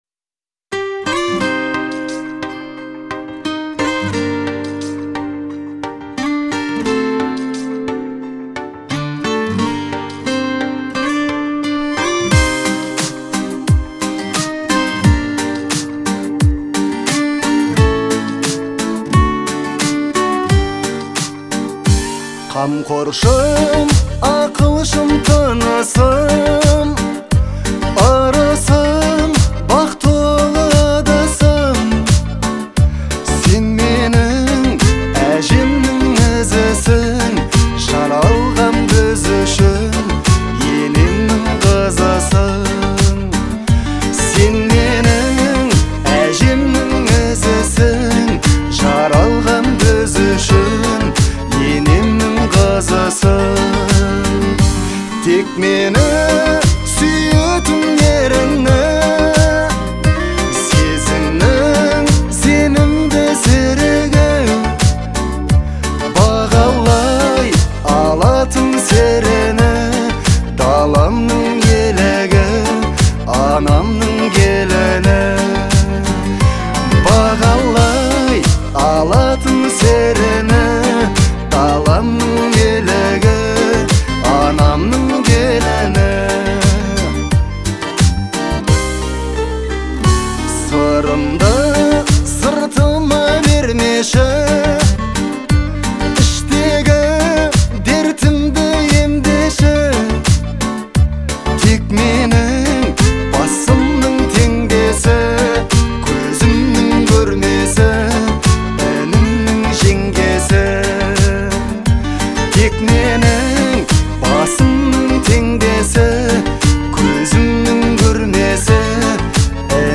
в жанре казахской поп-музыки